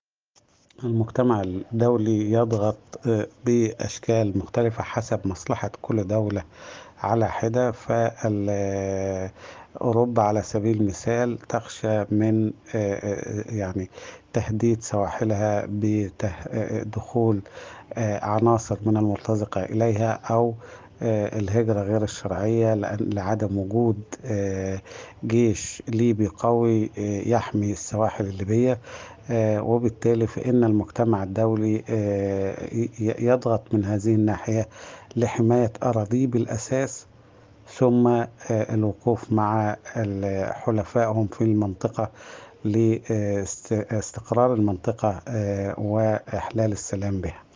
محلل سياسي وكاتب صحفي